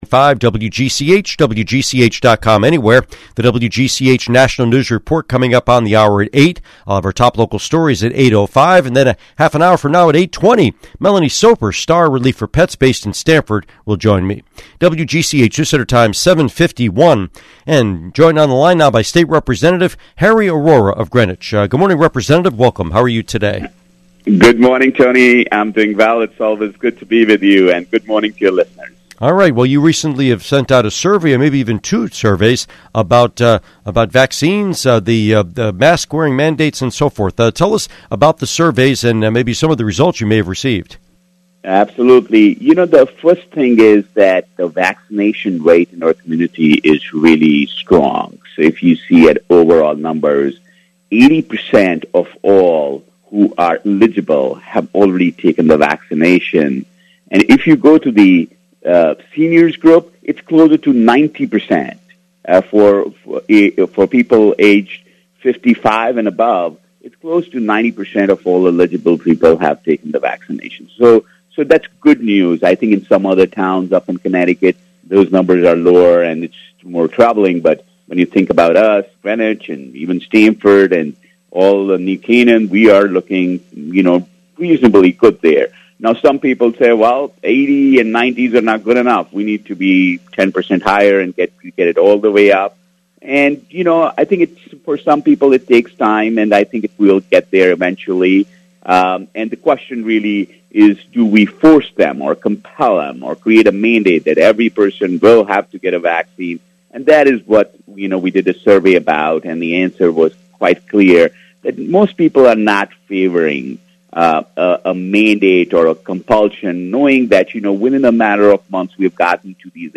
Interview with State Representative Harry Arora